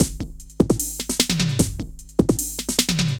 CRATE F DRUM 2.wav